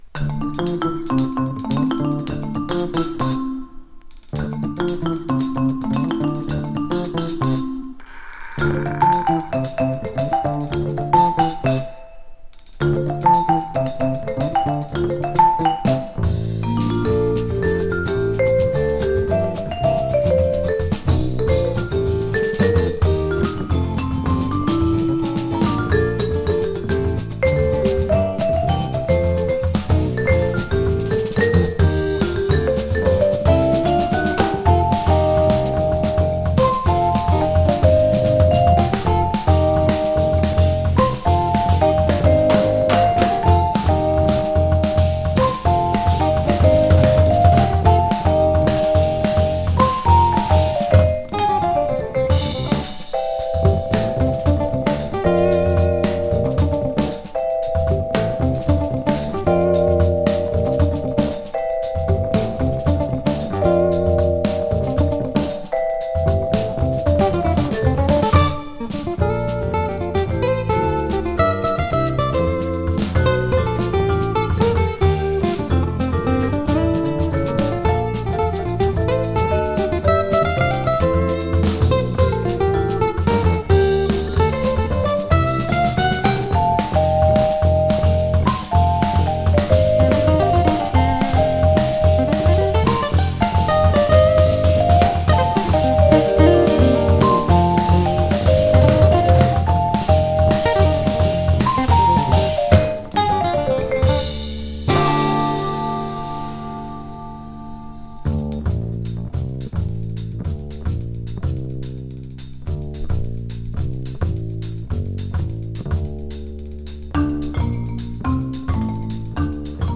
【FUSION】【JAZZ FUNK】